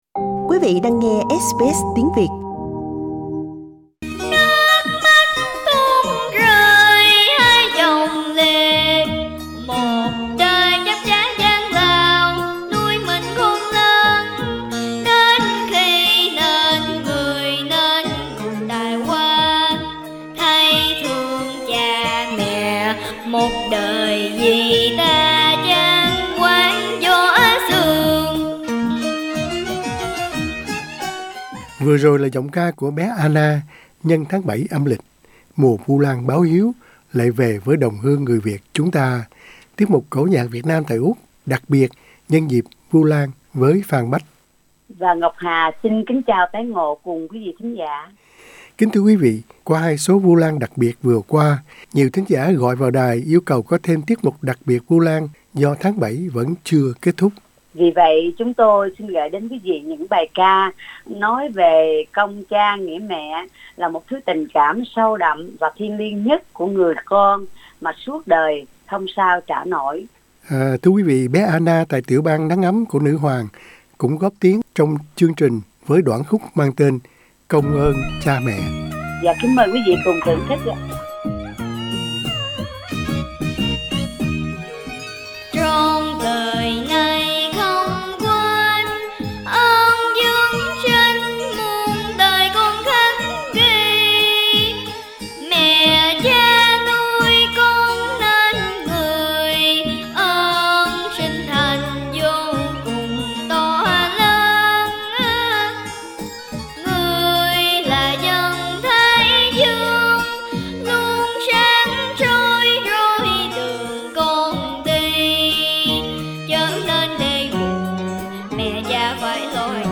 cổ nhạc